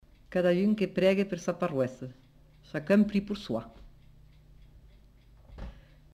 Lieu : Cathervielle
Effectif : 1
Type de voix : voix de femme
Production du son : récité
Classification : proverbe-dicton